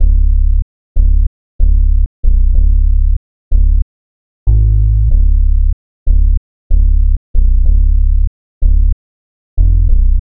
Ridin_ Dubs - Deep Bass.wav